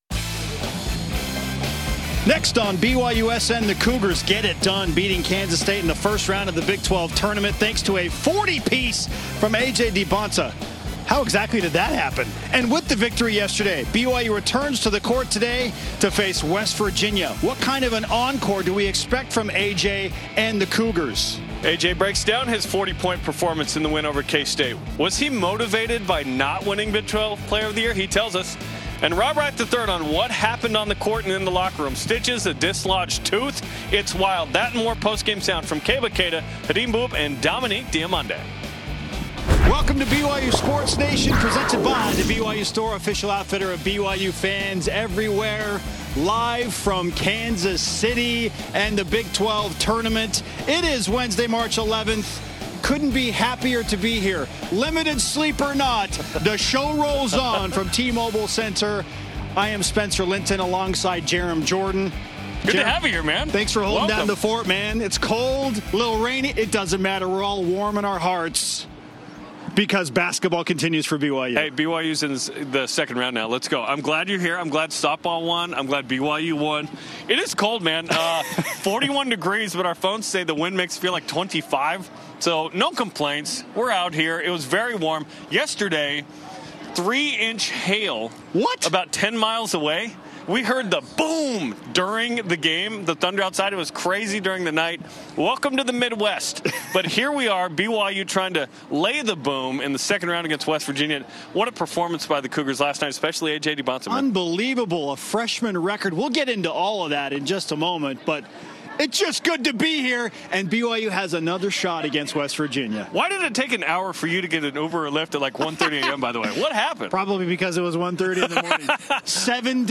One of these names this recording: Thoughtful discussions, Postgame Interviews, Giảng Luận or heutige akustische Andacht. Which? Postgame Interviews